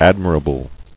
[ad·mi·ra·ble]